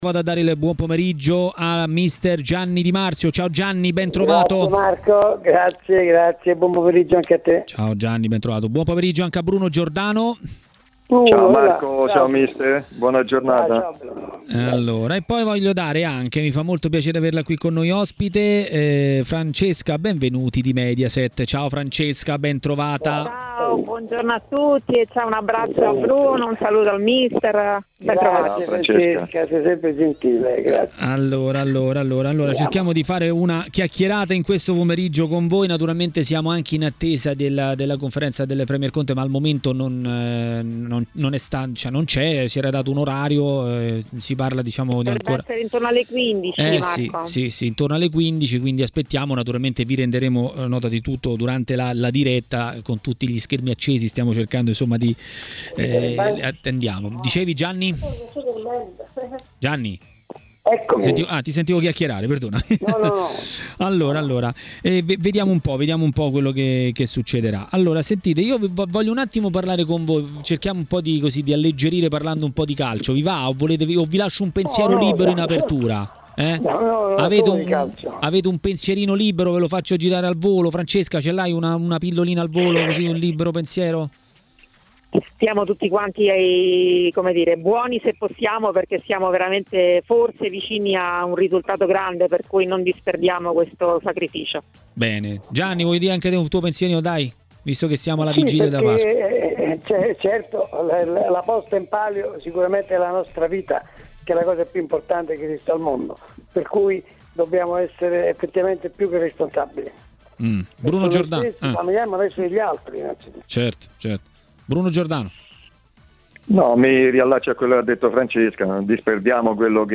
Bruno Giordano, ex calciatore e tecnico, ha parlato dell'Inter in diretta su TMW Radio, durante Maracanà.